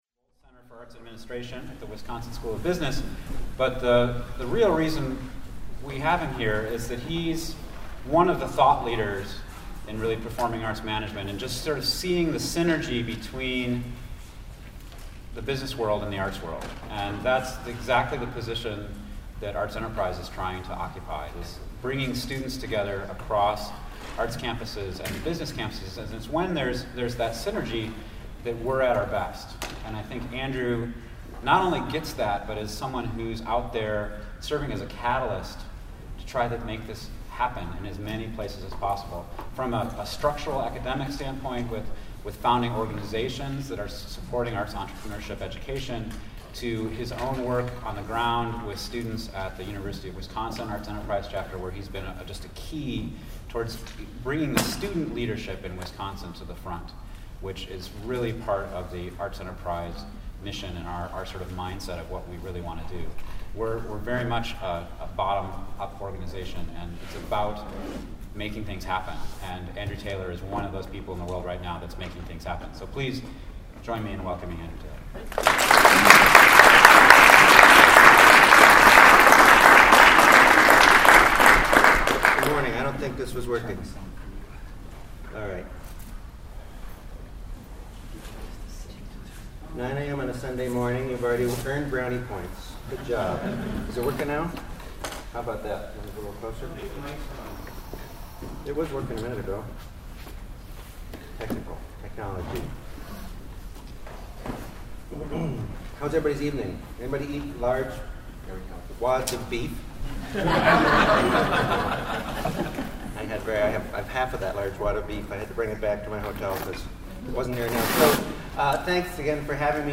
The way you design your creative organization or arts career has a huge impact on the value you create with it and draw from it -- in money, in productivity, in work/life balance, in creative satisfaction. This keynote and conversation will explore the inner workings of a business model, and how arts organizations and artists are connecting those elements in dynamic and innovative ways.